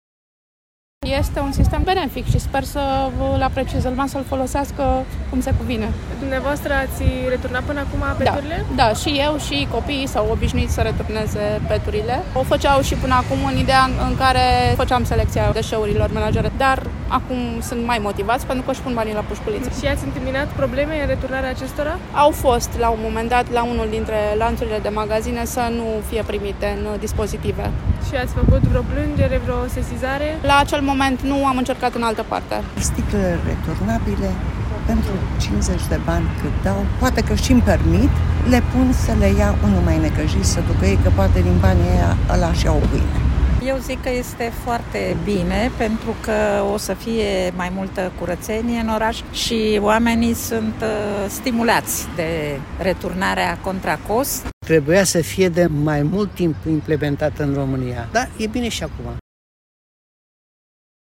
La câteva luni de la implementare, Sistemul Garanție Returnare s-a dovedit a fi un succes, la Brașov. Cel puțin așa consideră brașovenii, care s-au declarat mulțumiți de funcționarea acestui sistem, cu atât mai mult cu cât pot primi bani în schimbul ambalajelor reciclabile și în plus orașul devine mai curat.
vox-reciclare.mp3